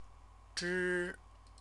chinese_characters_zhi-4_zhi-4.mp3